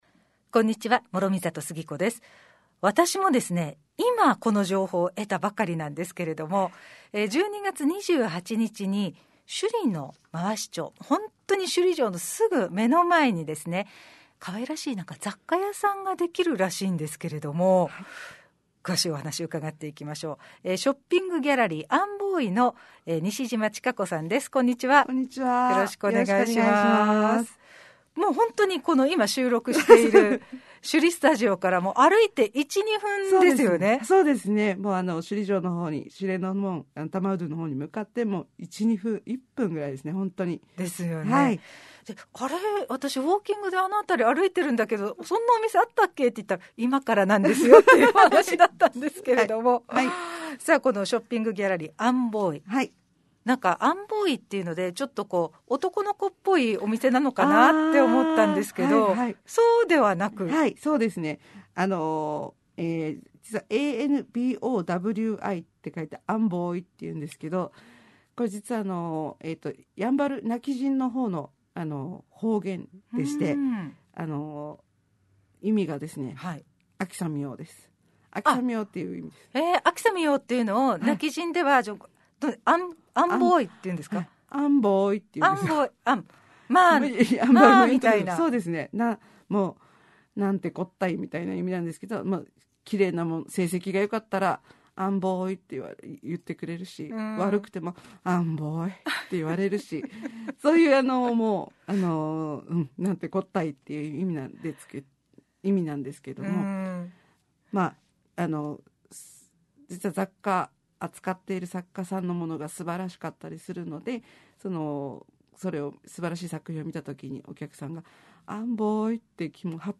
221223「アンボウイ ショッピングギャラリー」首里城公園近くの工芸品や雑貨を扱うセレクトショップインタビュー